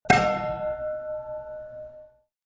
Descarga de Sonidos mp3 Gratis: campana 17.